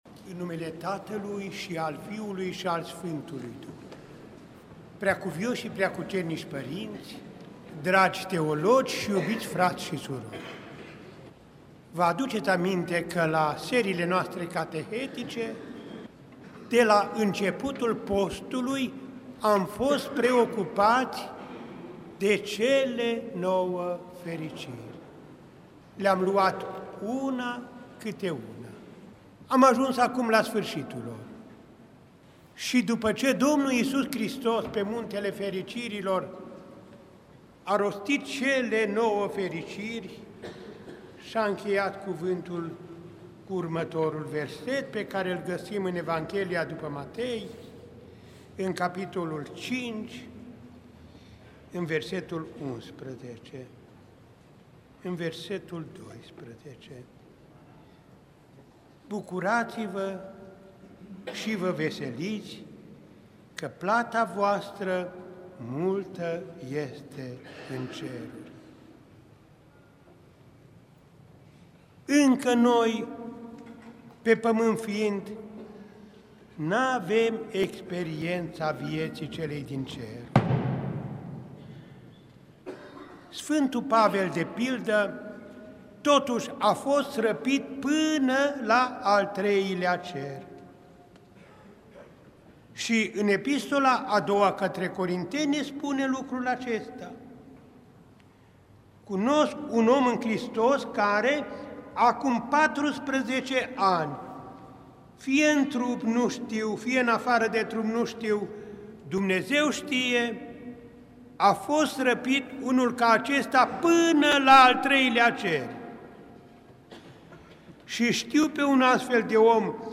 apr. 2, 2017 | Catehezele Mitropolitului Andrei